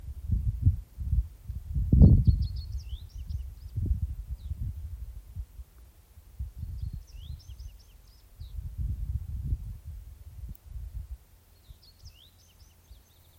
Птицы -> Овсянковые ->
камышевая овсянка, Emberiza schoeniclus